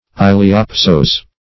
Search Result for " iliopsoas" : The Collaborative International Dictionary of English v.0.48: Iliopsoas \Il`i*o*pso"as\, n. (Anat.)
iliopsoas.mp3